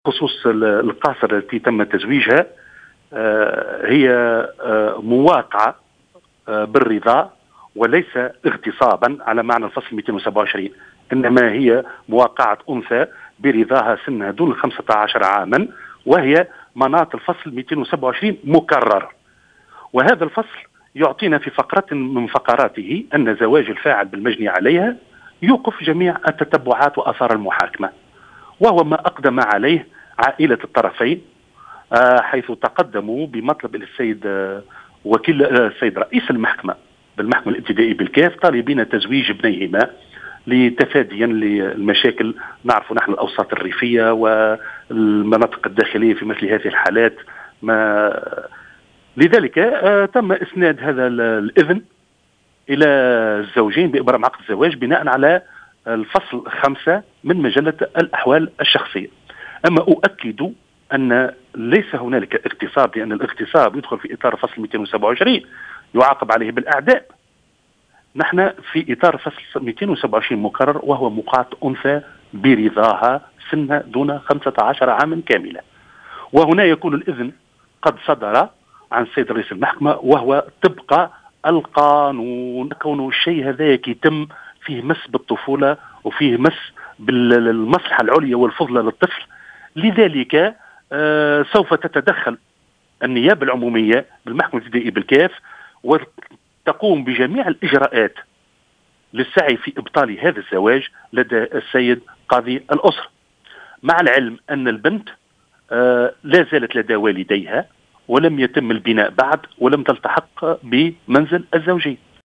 قال وكيل الجمهورية بالمحكمة الابتدائية بالكاف، شكري الماجري في تصريح لمراسل "الجوهرة أف أم" إن النيابة العمومية بالمحكمة الابتدائية بالكاف ستتدخل وتقوم بكل الإجراءات لإبطال قرار زواج طفلة الـ13 سنة حفاظا على مصلحتها، مشيرا إلى أن الطفلة المذكورة لم تلتحق بعد بمنزل الزوجية.
وكيل الجمهورية بالمحكمة الابتدائية بالكاف